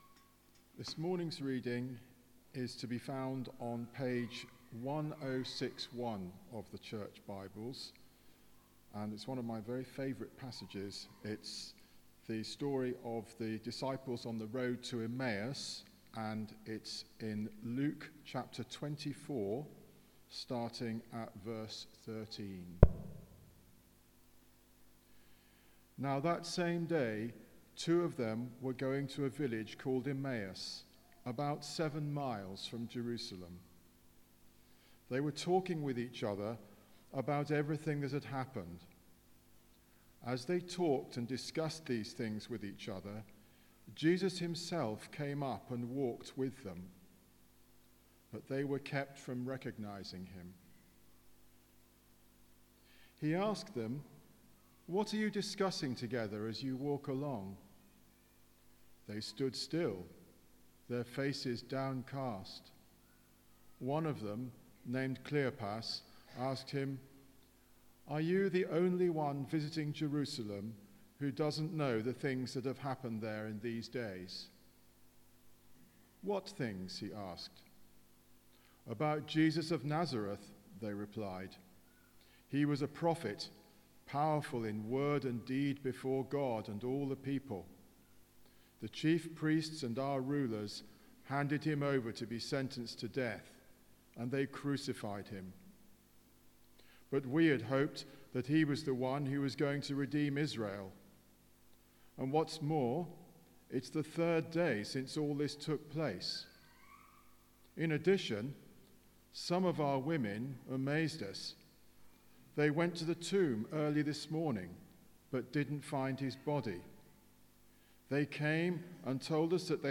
Bible Text: Luke 24: 13-49 | Preacher